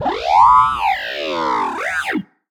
sad2.ogg